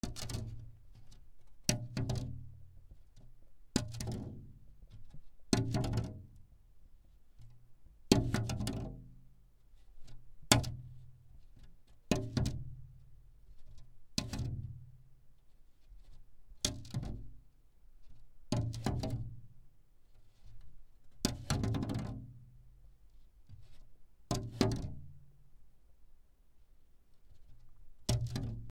プラスチック板を置く
/ M｜他分類 / L01 ｜小道具